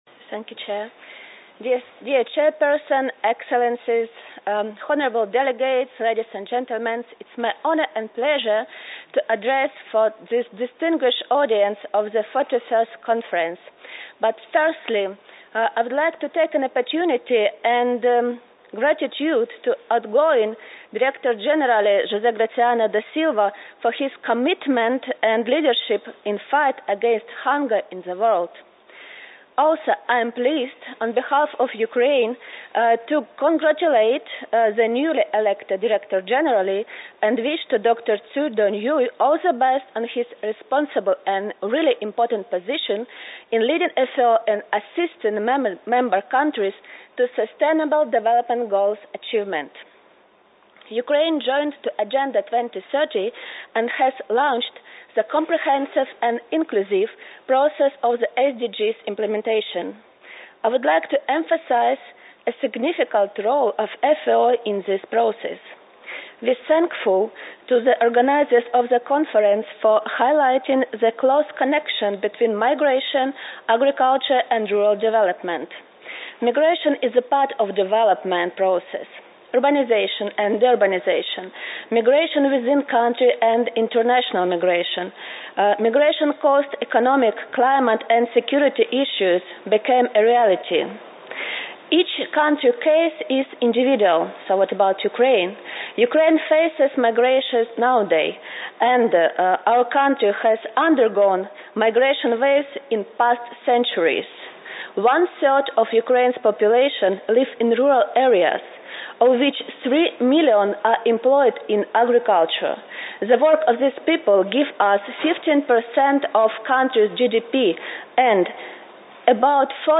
Addresses and Statements
Her Excellency Olena Kovalova, Deputy Minister of Agrarian Policy and Food of Ukraine